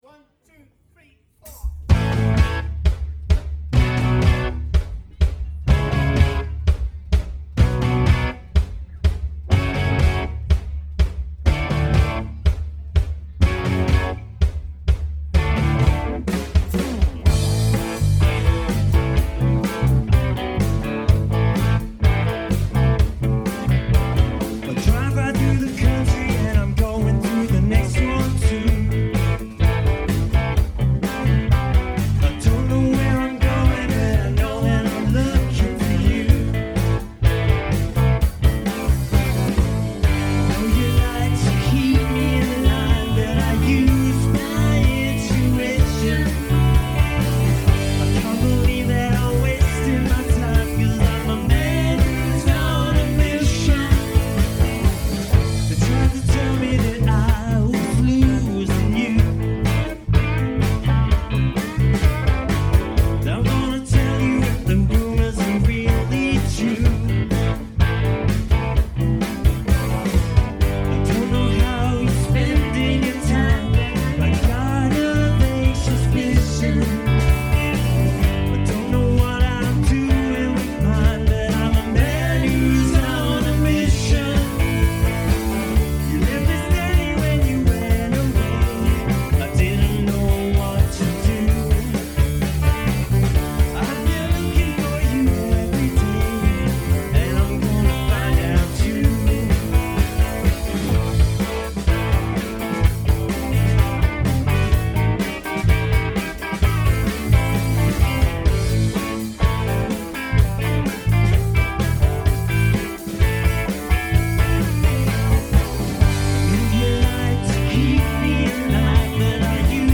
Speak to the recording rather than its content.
POMME D'OR CONCERT